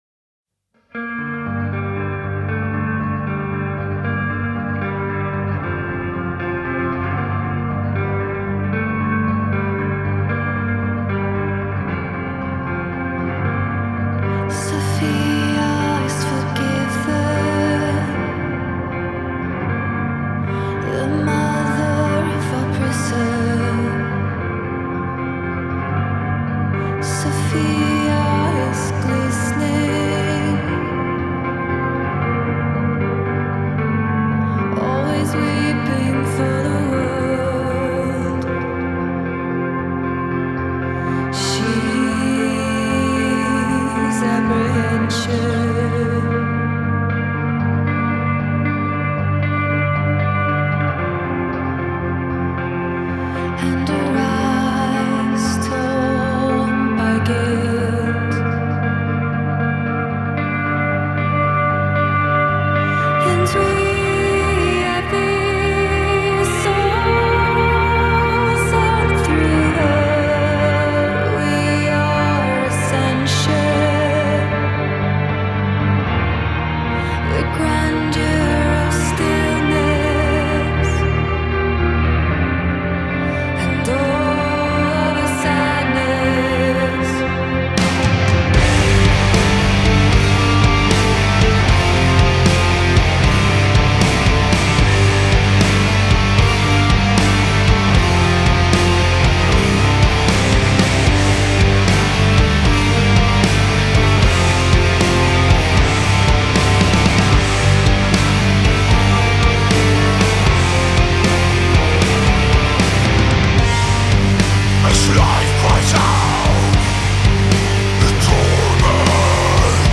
gothic doom